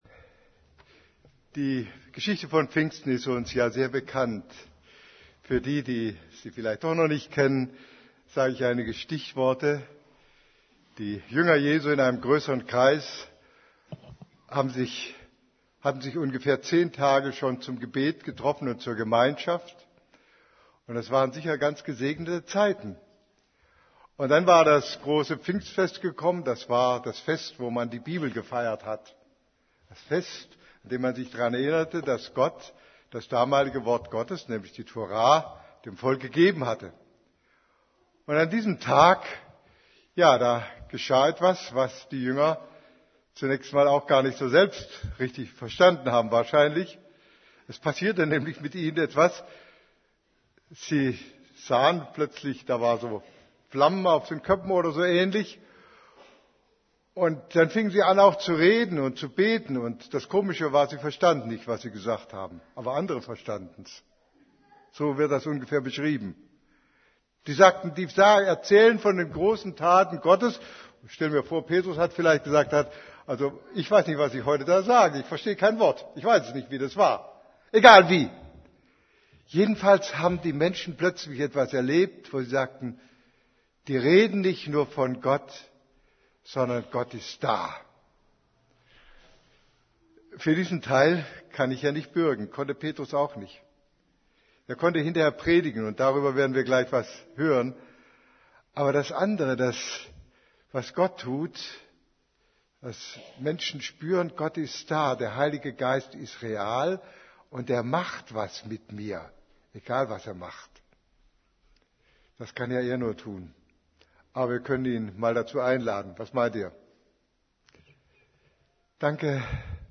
> Übersicht Predigten Bewährung oder Bekehrung Predigt vom 12.